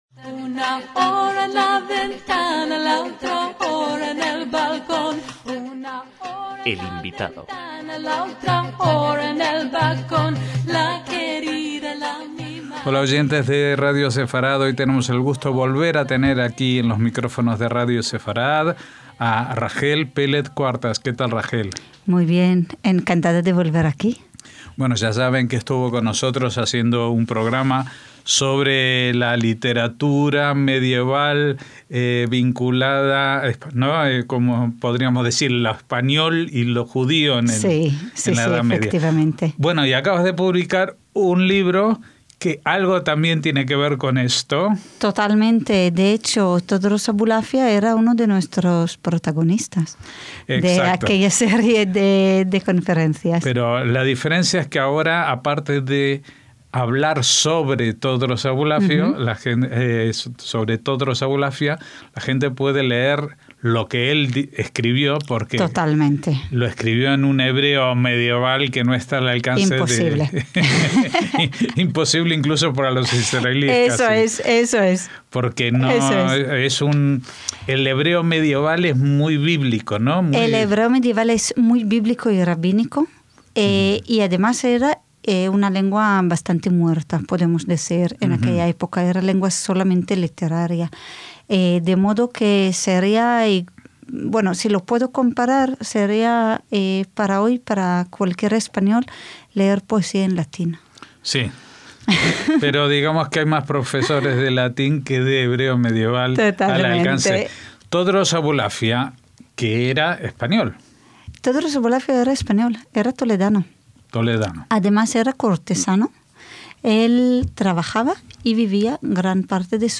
Este lunes 23 de septiembre se presentó en el Centro Sefarad-Israel el libro "Poemas selectos" de Todros Abulafia